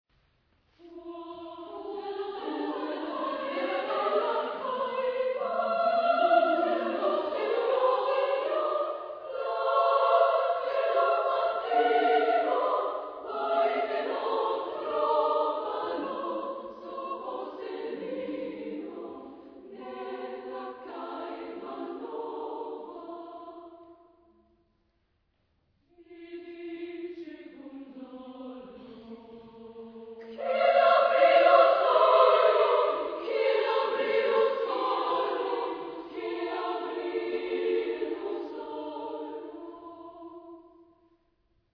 Genre-Style-Forme : Profane ; contemporain ; Madrigal
Tonalité : la majeur ; la mineur